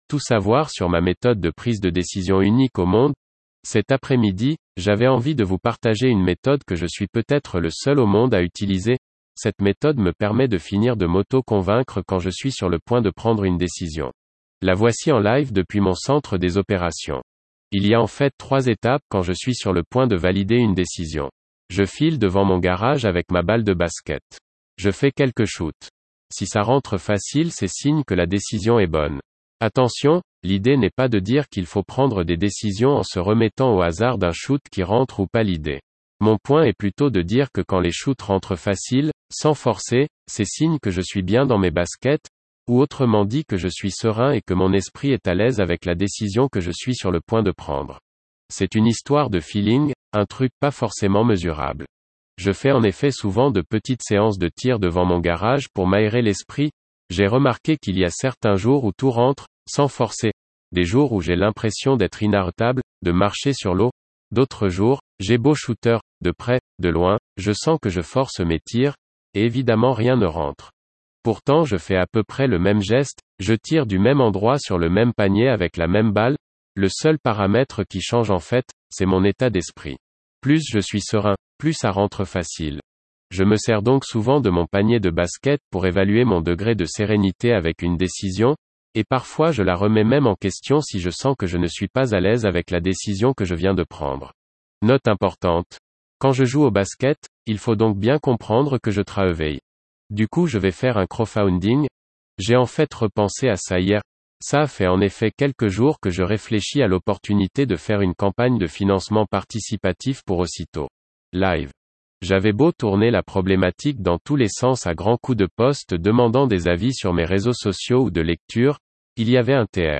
Cette méthode me permet de finir de m'auto-convaincre quand je suis sur le point de prendre une décision. La voici en live depuis mon centre des opérations.